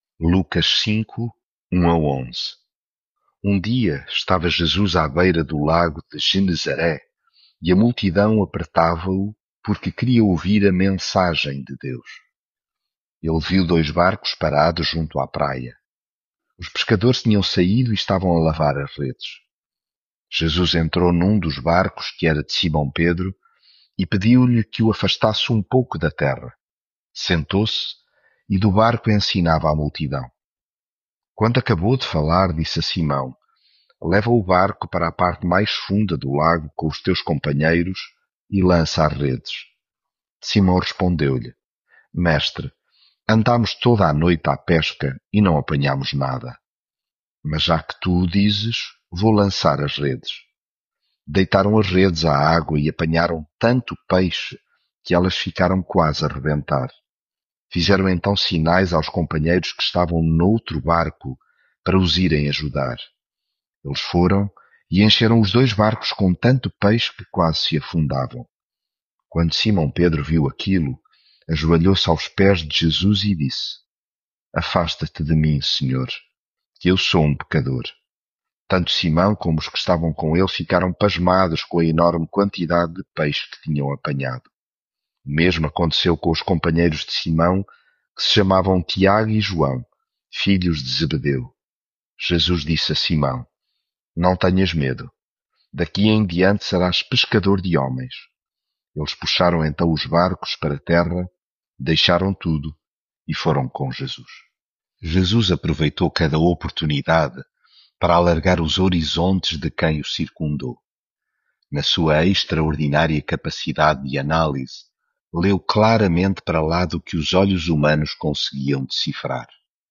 Devocional
leitura bíblica